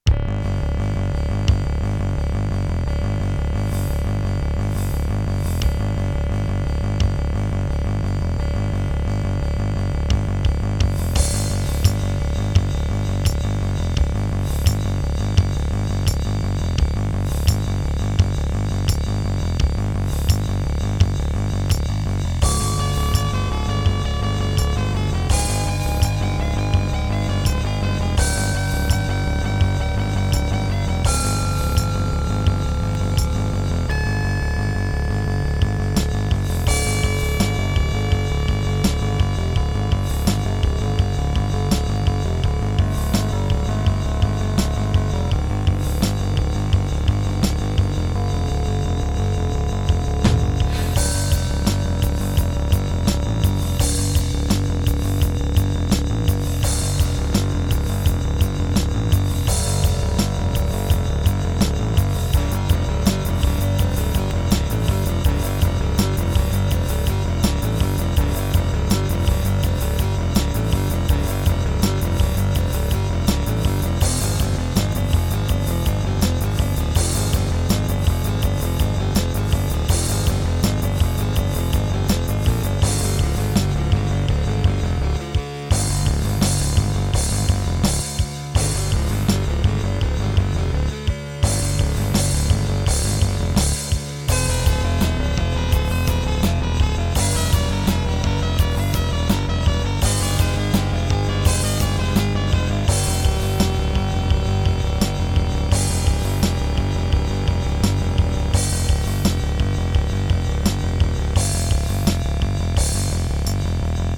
Music: GM midi
Gravis Ultrasound PNP (GM emulation megaem)